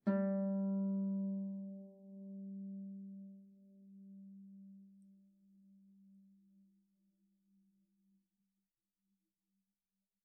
KSHarp_G3_mf.wav